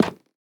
Minecraft Version Minecraft Version latest Latest Release | Latest Snapshot latest / assets / minecraft / sounds / block / cherrywood_trapdoor / toggle2.ogg Compare With Compare With Latest Release | Latest Snapshot